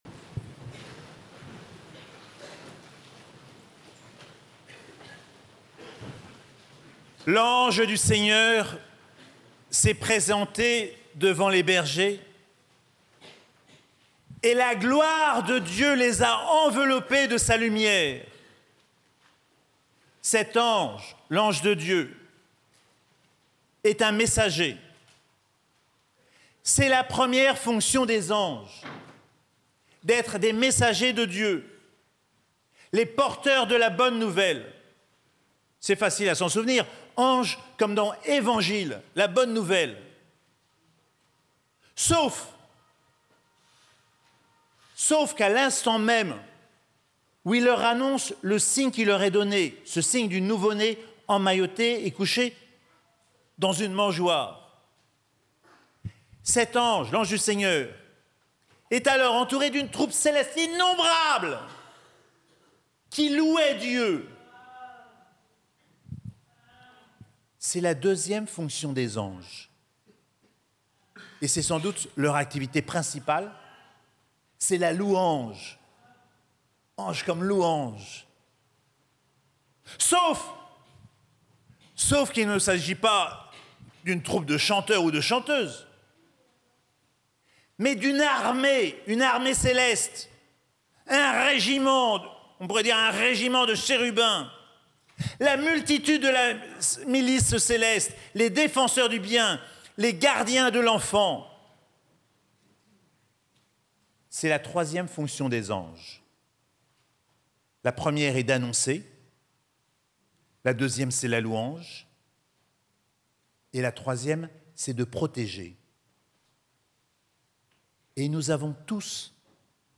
Messe de Noël - 24 et 25 décembre 2024